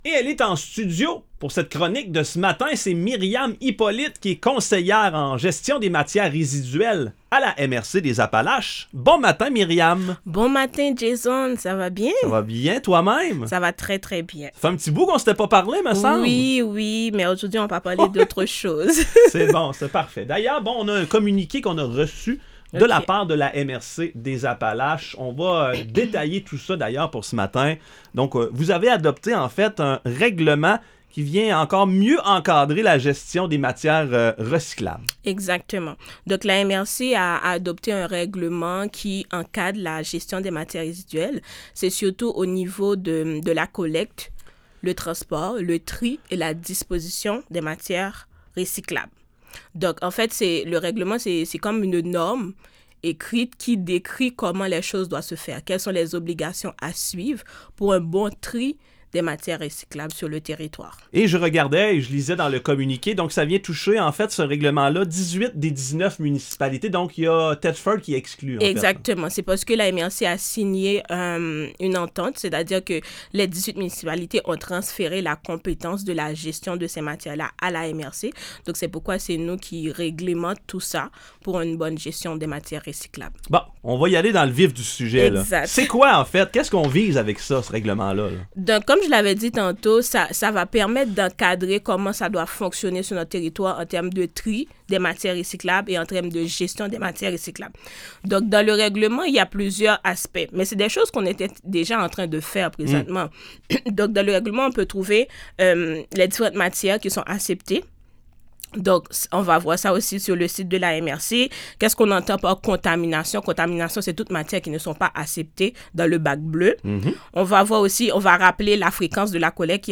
Chroniques radio
Les chroniques radio de la MRC des Appalaches sont diffusées à la station de radio VIVA 105,5, les mercredis chaque deux semaines, vers 8h00.